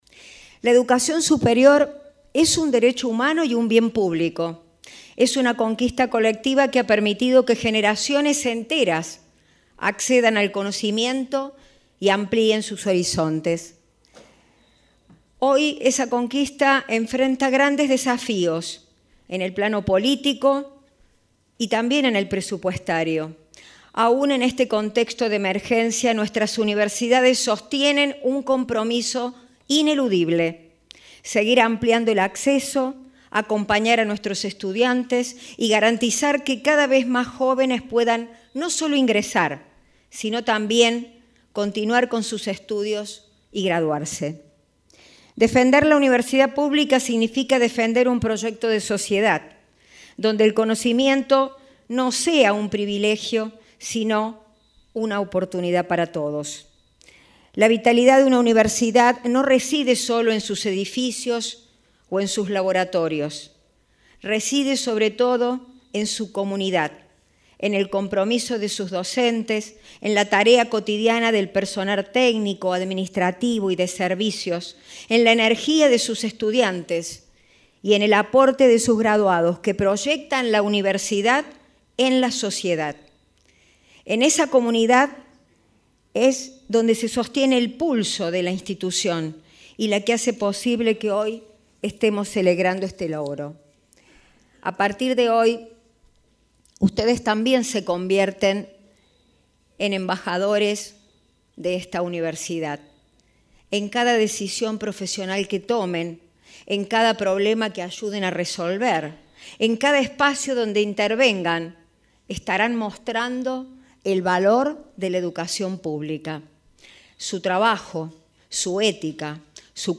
En el mensaje a los graduados, la rectora Marisa Rovera hizo referencia a las dificultades económicas que afecta a las universidades nacionales y el esfuerzo para poder seguir cumpliendo su función.